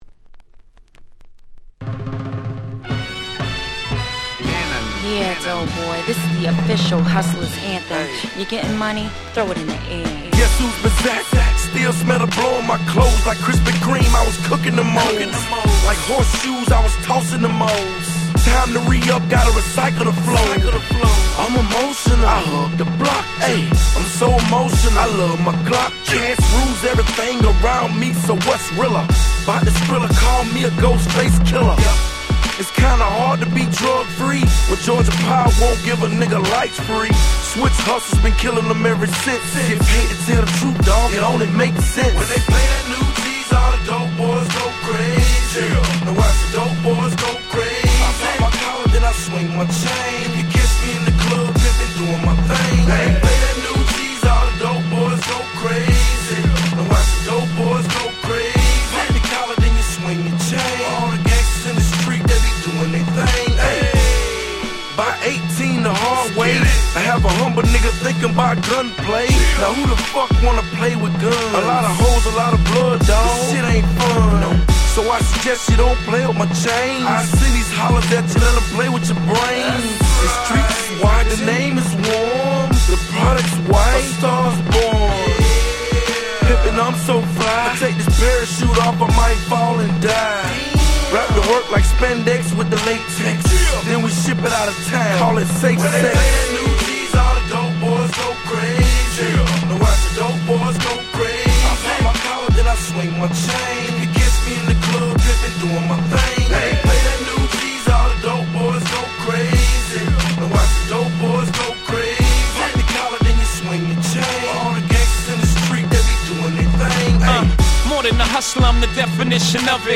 05' Smash Hit Southern Hip Hop !!
00's トラップ サウス